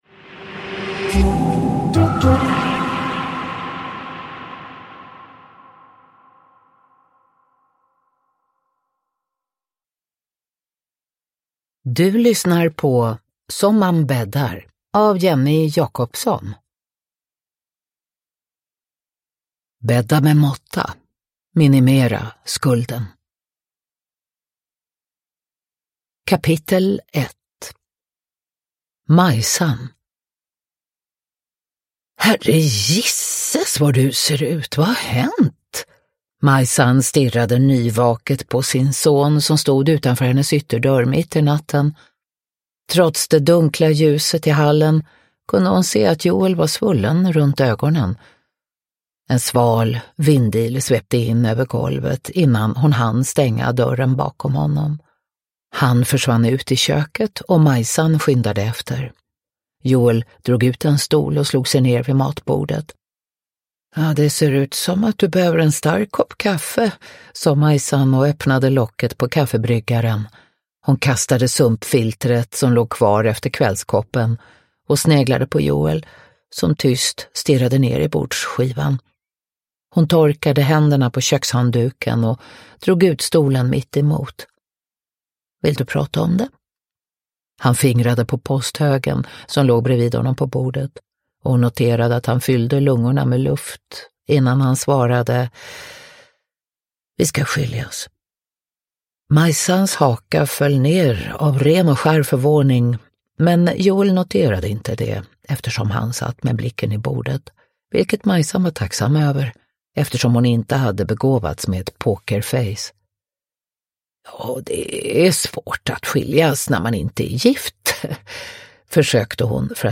Som man bäddar – Ljudbok – Laddas ner
Uppläsare: Katarina Ewerlöf